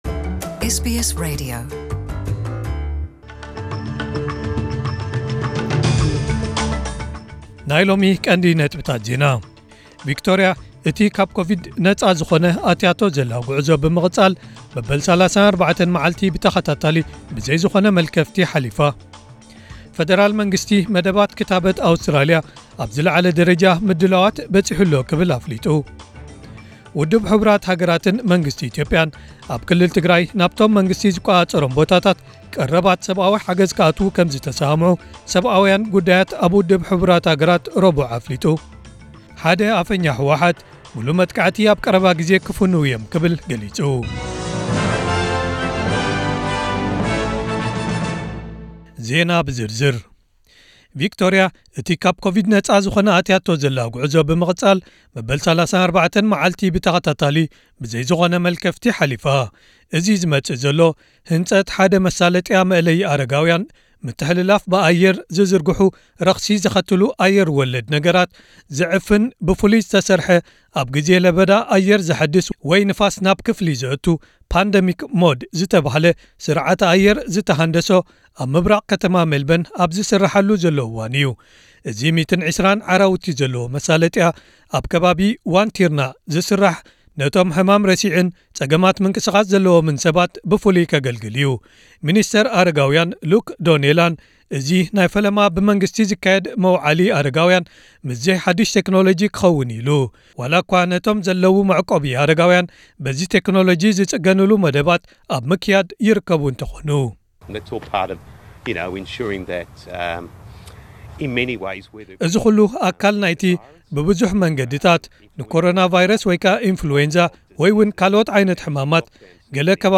ዕለታዊ ዜና ኤስቢኤስ ትግርኛ (03/12/2020)